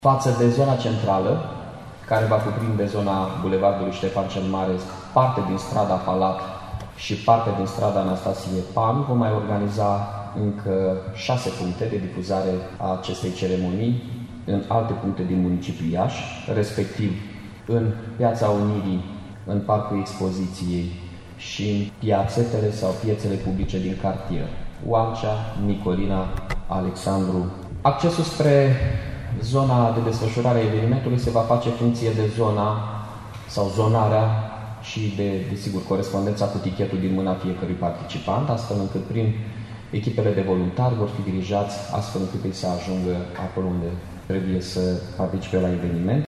Fiecare sector va avea un punct de prim ajutor, deservit de SMURD, iar toate spitalele vor fi în gardă pentru orice necesitate, după cum a declarat primarul Mihai Chirica: